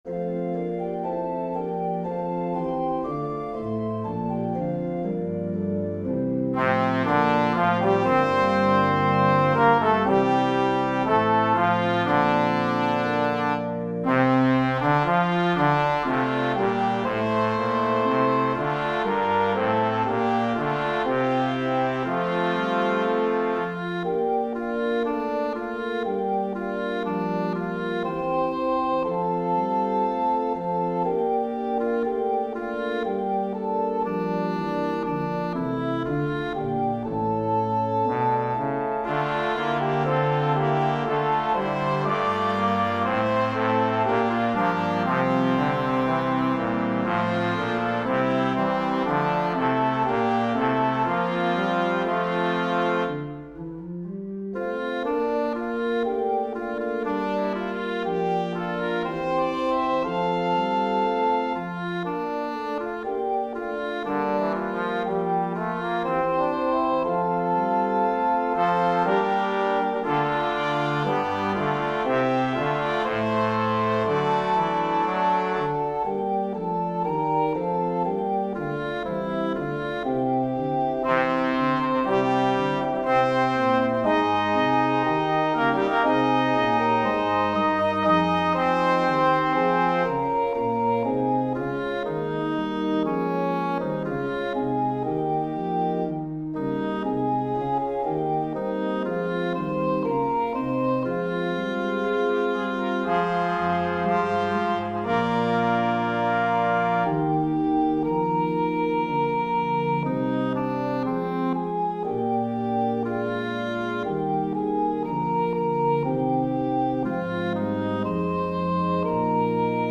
Version 1: Assembly and Organ w/ opt. Brass
Version 2: SATB and Organ w/ Brass and Assembly